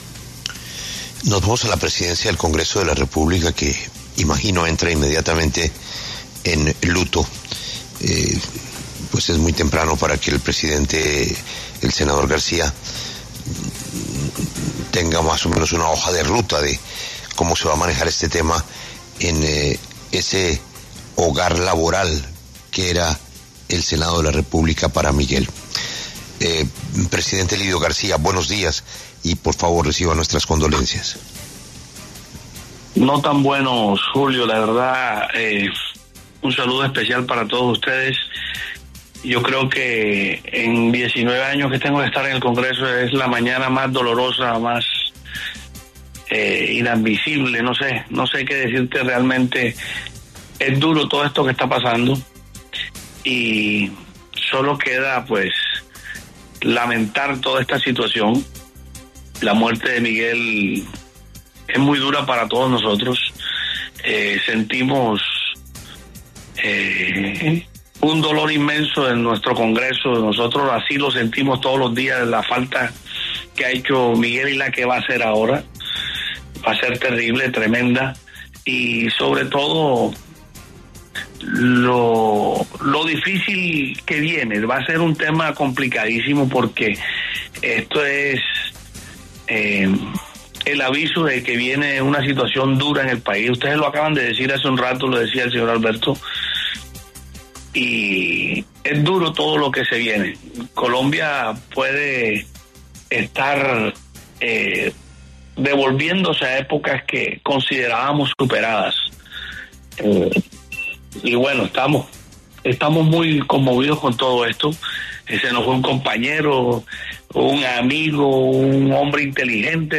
Lidio García, presidente del Senado de la República, habló en La W acerca de la muerte del senador Miguel Uribe Turbay.
En entrevista con La W, el presidente del Congreso, senador Lidio García, expresó dolor y preocupación por el asesinato de Miguel Uribe Turbay.